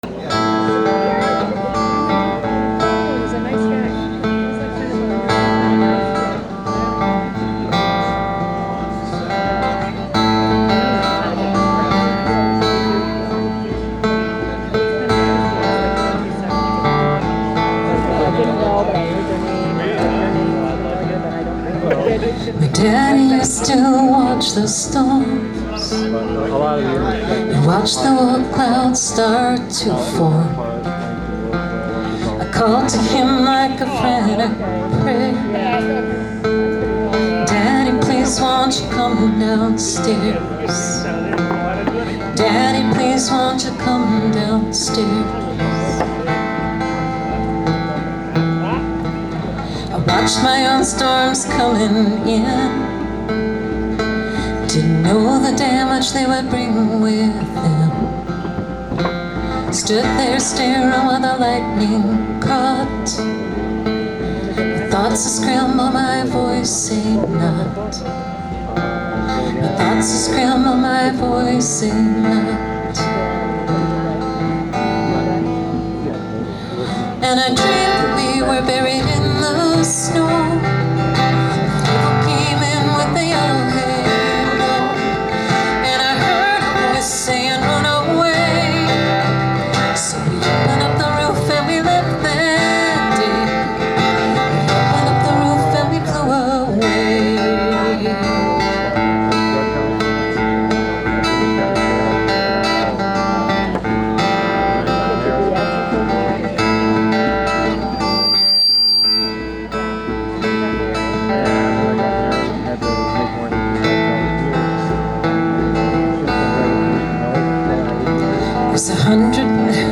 on percussion
singing and playing guitar